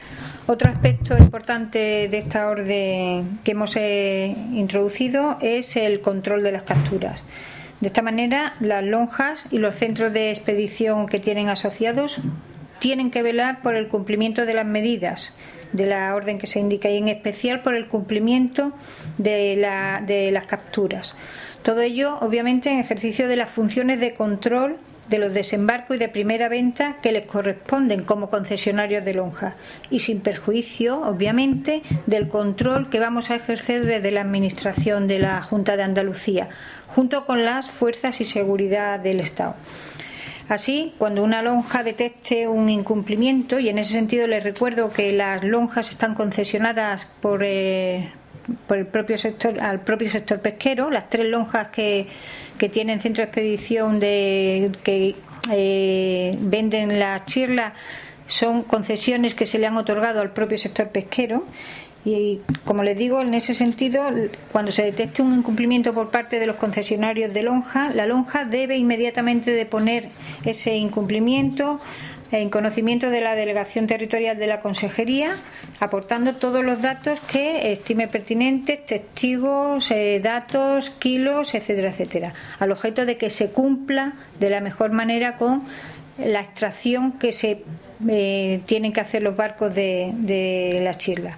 Declaraciones de Margarita Pérez sobre el objetivo de la reunión con el sector de la chirla